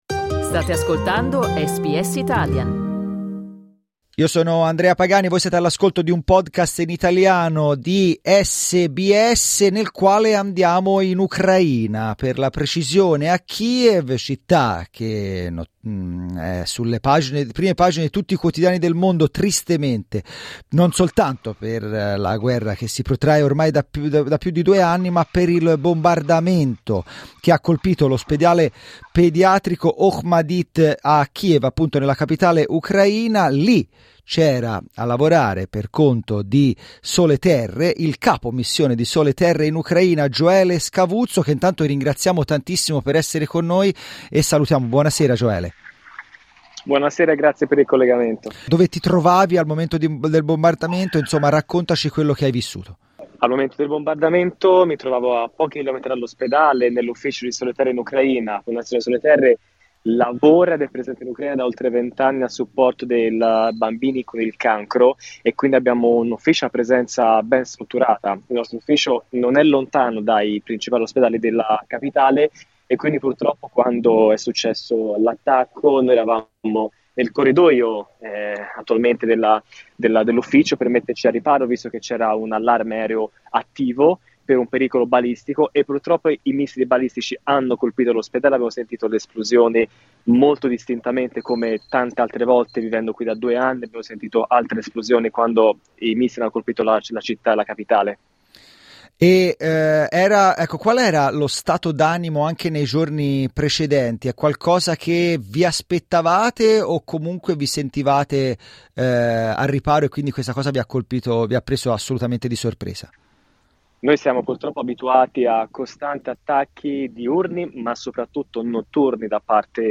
Bombe sull'ospedale pediatrico a Kiev, la testimonianza di un operatore